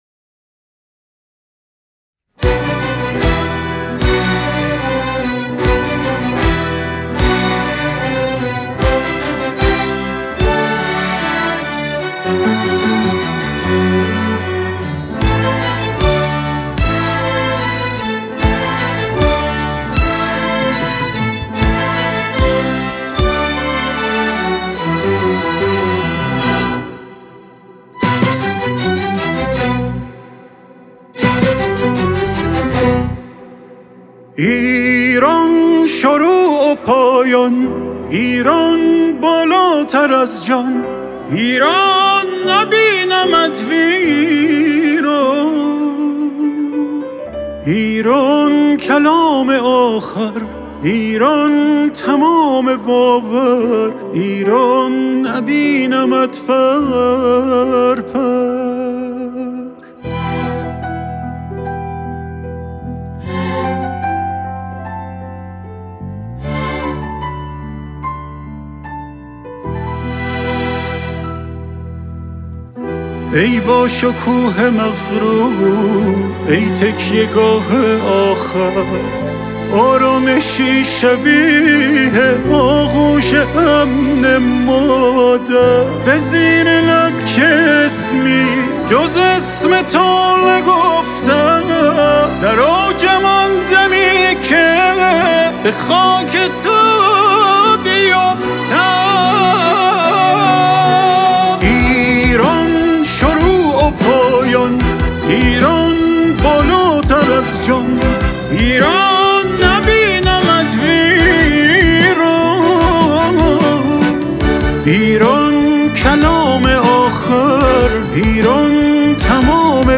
دموی منتشر شده در صفحه اینستاگرام